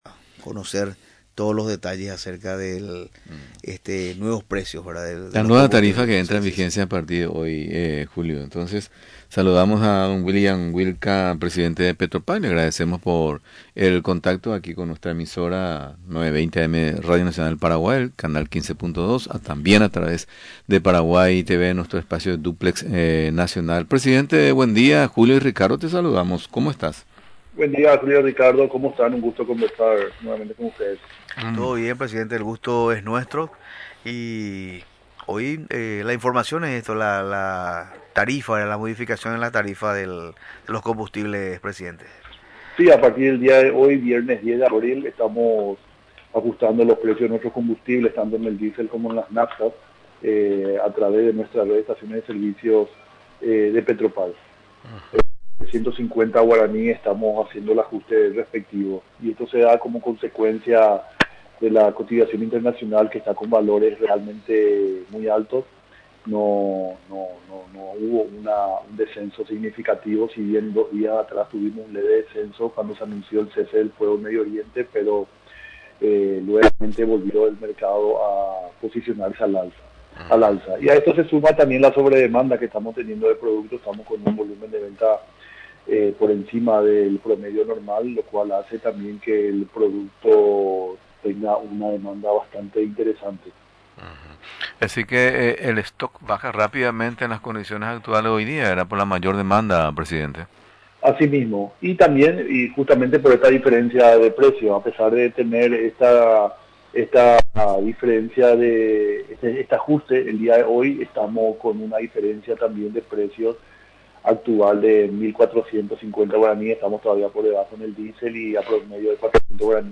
Durante la entrevista en Radio Nacional del Paraguay, explicó que la estrategia es ir atenuando los costos.